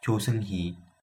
Pronunciation of Cho Seung-hui, by a male speaker of the Seoul dialect